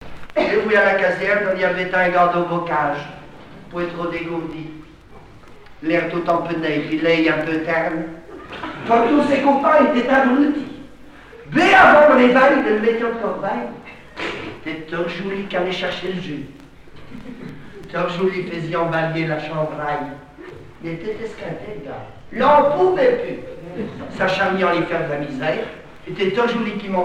Patois local
Genre sketch